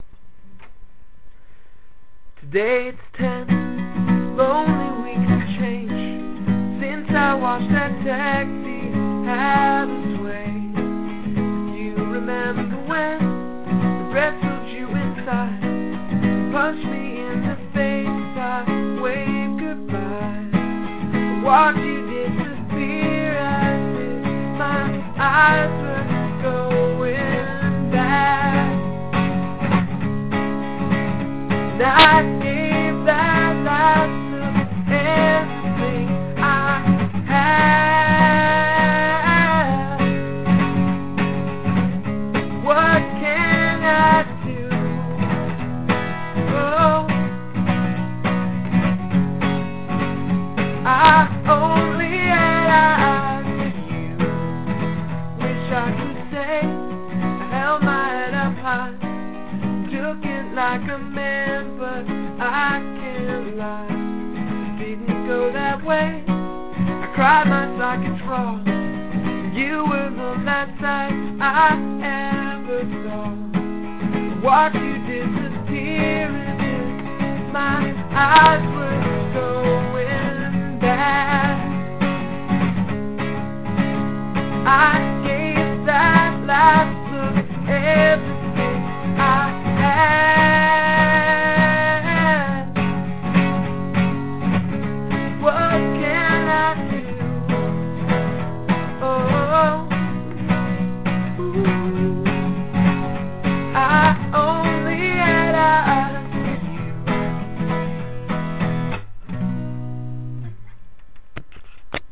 I Only Had Eyes For You (Crappy Demo) - MP3
verse: B/F# G+ c#/G# E/G#
chorus: c# E F# c#, B F# c# E
wow!!!! this song is wonderful. the verse progression isn't one i would expect to hear in a fast pop song, and that works very nicely. mopey subject matter set to a fast pop beat is the best. i really like how the chorus and the verse roll into each other seamlessly, the chorus just sneaks up and clobbers you and then you are right back into the verse. add more choruses! your voice sounds really nice up high like this
yeah, the recording is utter junk, made on my little usb mp3 player. that microphone is so small it can't be seen by the naked eye.